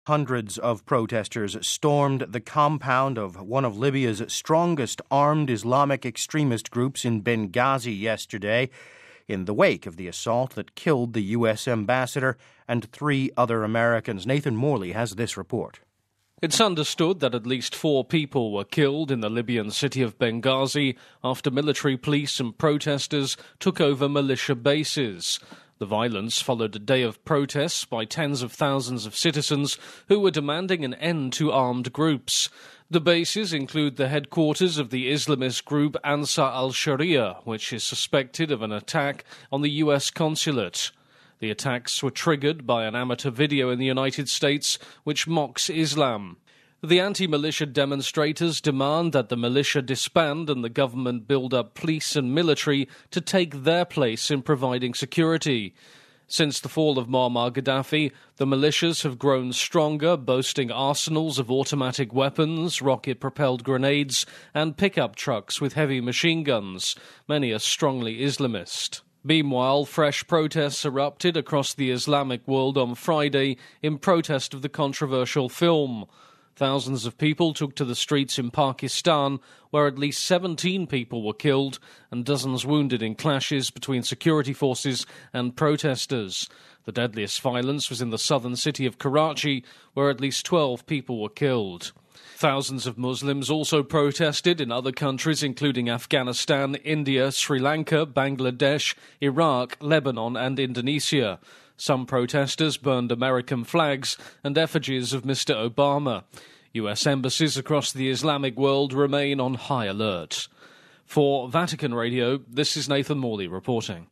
(Vatican Radio) Hundreds of protesters stormed the compound of one of Libya's strongest armed Islamic extremist groups in Benghazi on Friday, in the wake of the assault that killed the U.S. ambassador and three of his staff. The anti-militia demonstrators demanded that the militias disband and that the government build a police force to provide security.